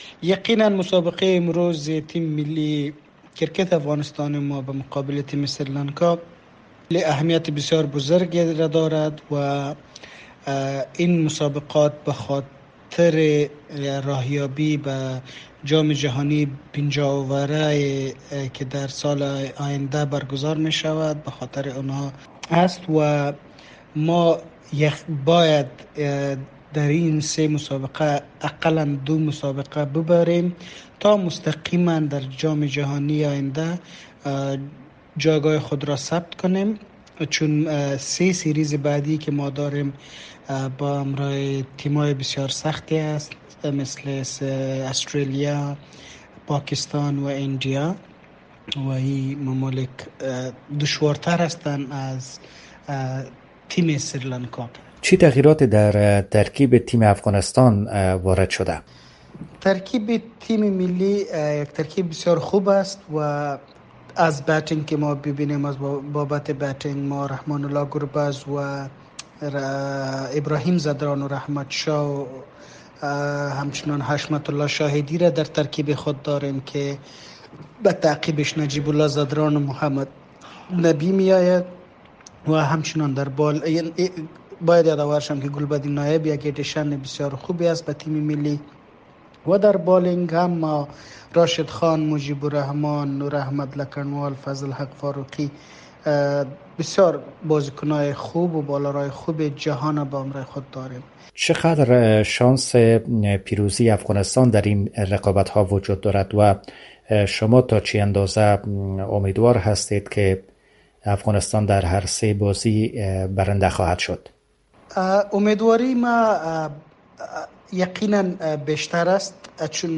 گفتگو کرده و نخست از وی پرسیده است که این رقابت ها برای افغانستان از چی اهمیتی برخوردار است؟